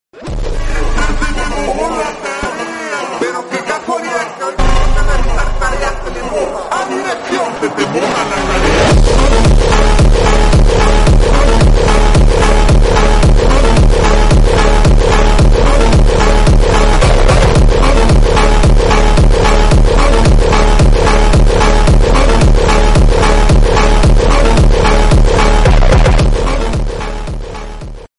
Hardstyle